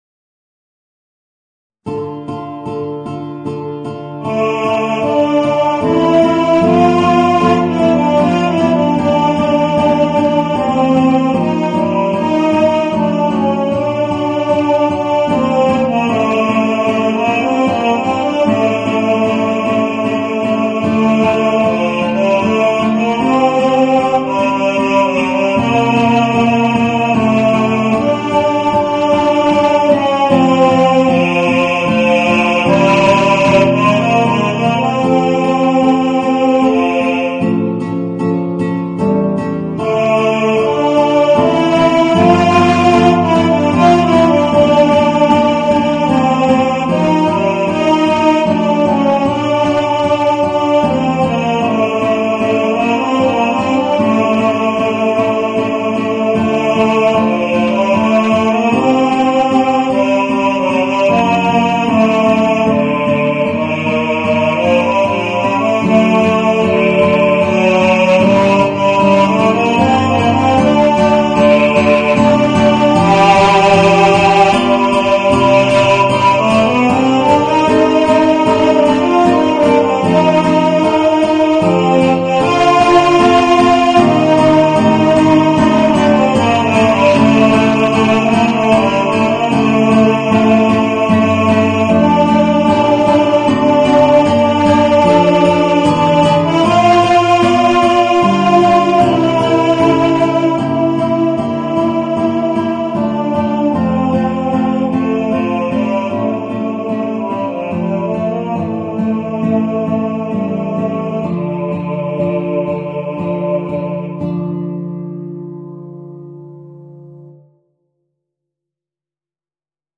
Voicing: Guitar and Baritone